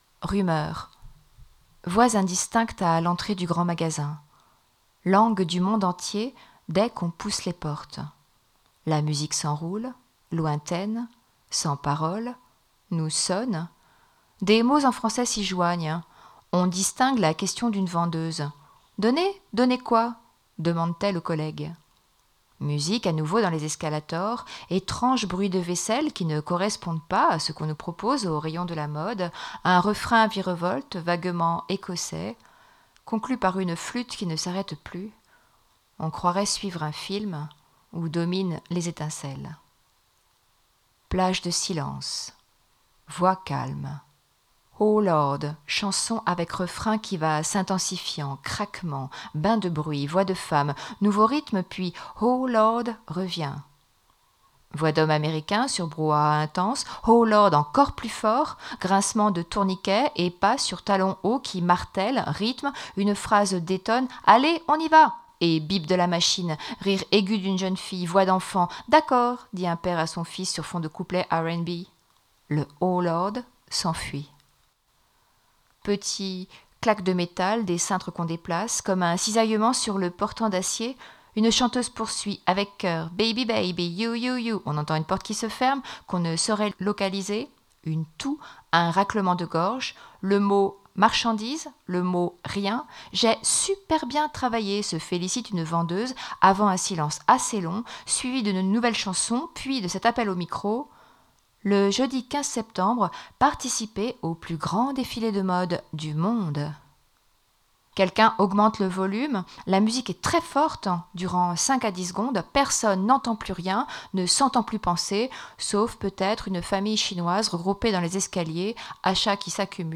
lecture_decor_lafayette_escalator.mp3